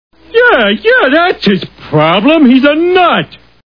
The Simpsons [Homer] Cartoon TV Show Sound Bites